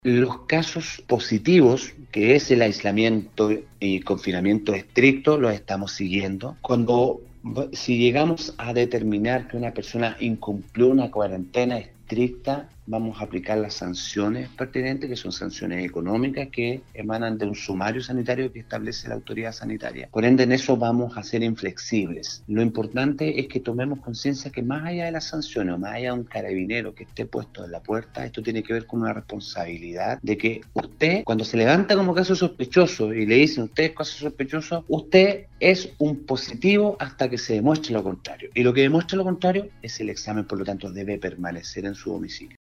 La mañana de este miércoles, el Director de Salud Atacama, Claudio Baeza, sostuvo un contacto telefónico con Nostálgica donde se refirió al aumento significativo de casos positivos de Covid-19 en la región de Atacama.